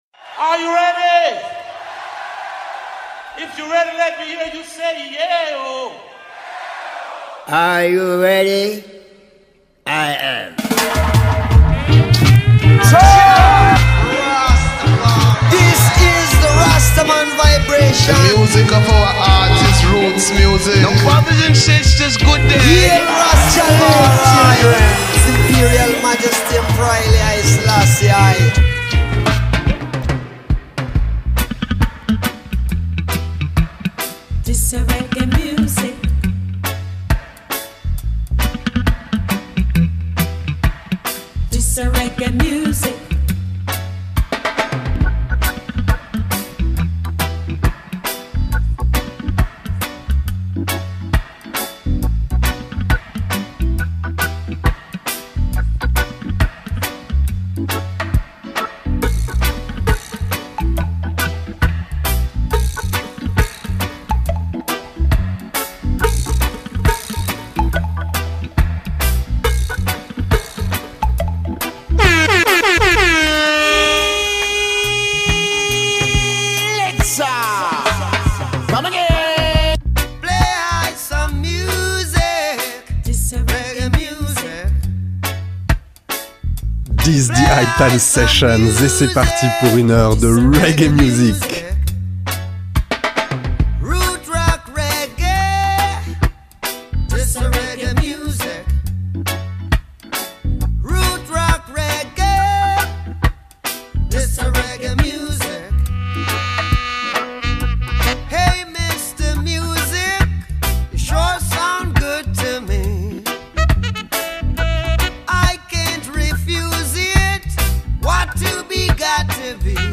Iceland reggae